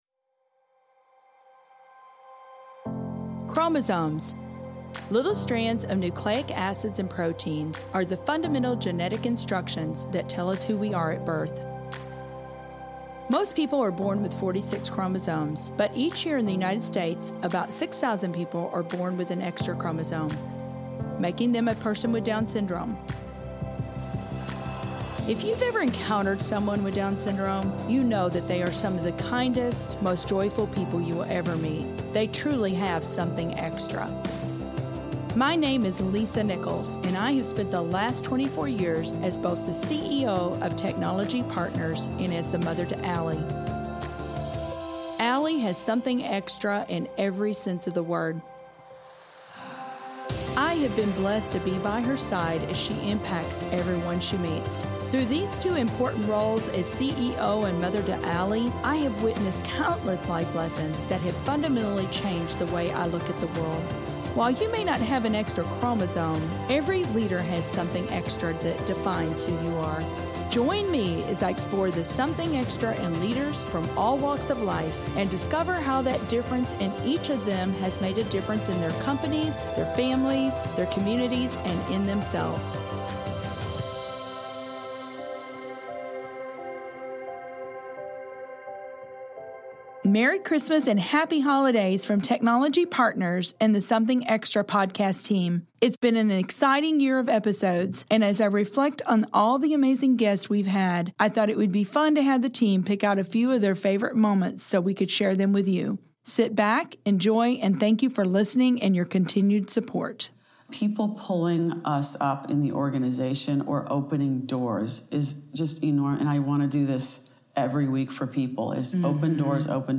Our gift to you this holiday season is a compilation of clips from your top-rated episodes of 2019.